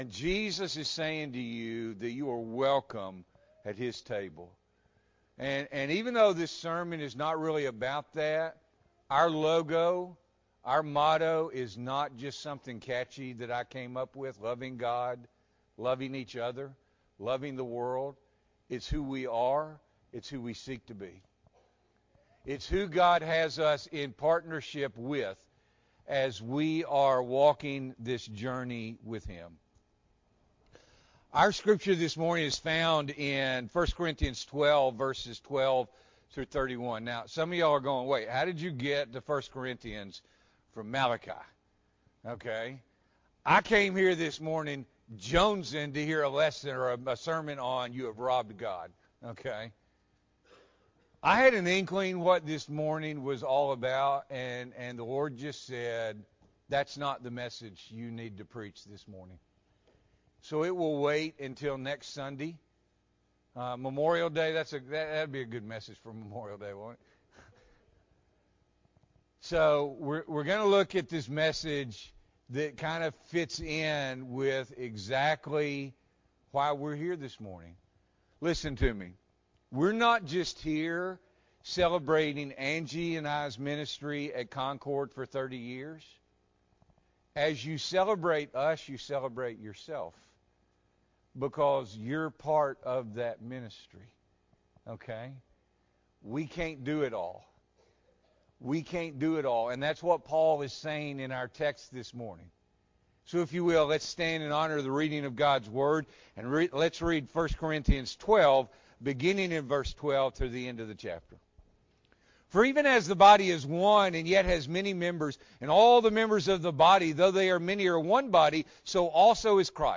May 22, 2022 – Morning Worship